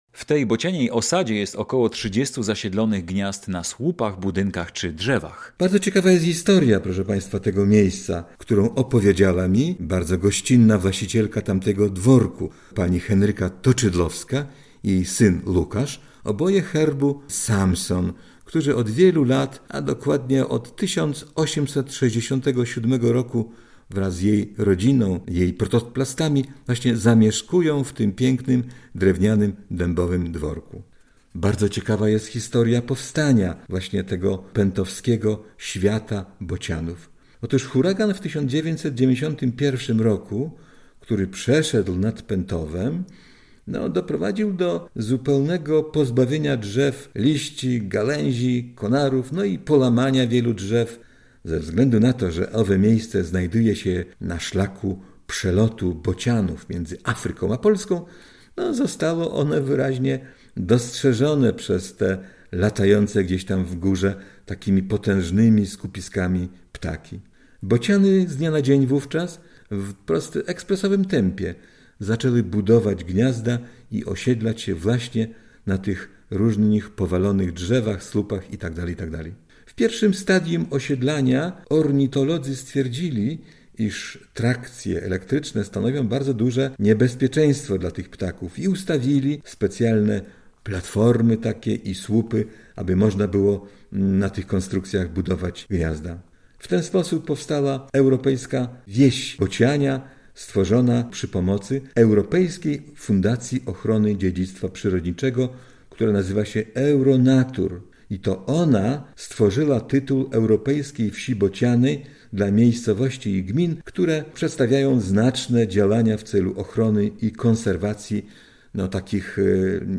Klekot w tym miejscu w sezonie wiosenno-letnim słychać codziennie.